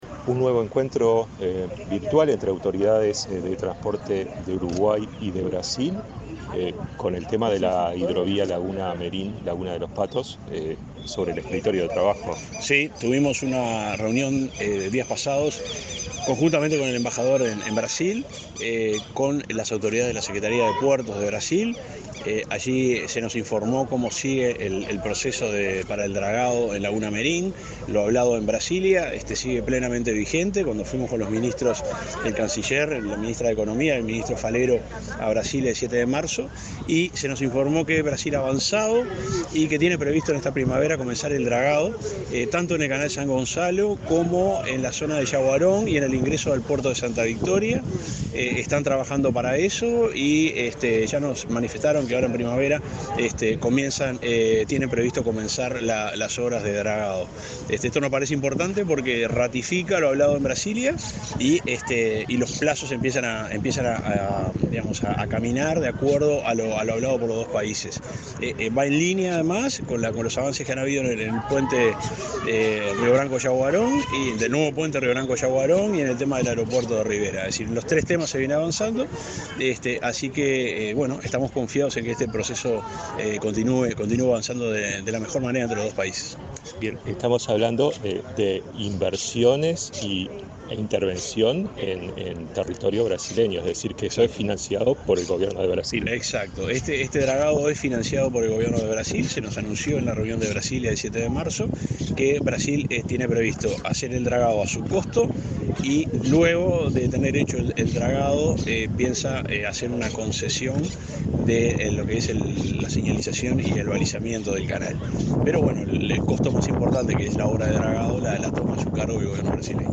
Entrevista al subsecretario de Transporte, Juan José Olaizola